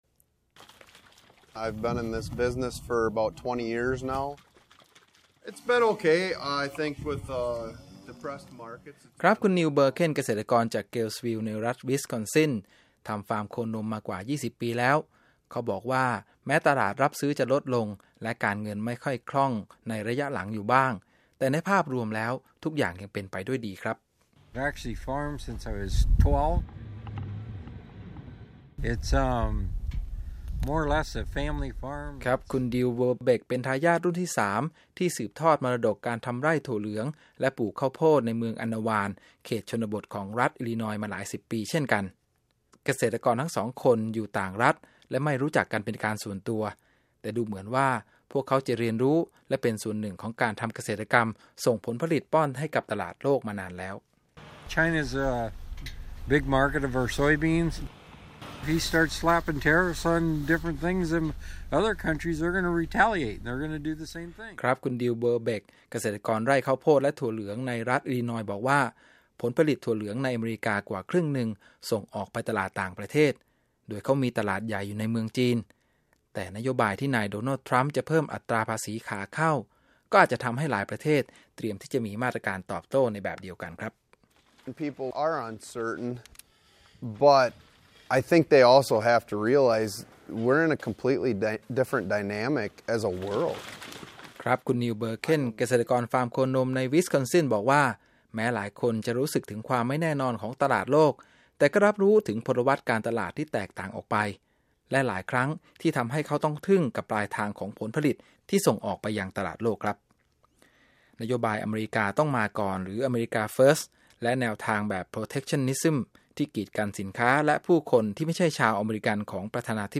รายงาน Off the Highwayมีเสียงสะท้อนจากเกษตรกรชาวอเมริกัน 2 คนจากต่างรัฐกับนโยบายที่ส่งผลต่อวิถีชีวิตของพวกเขา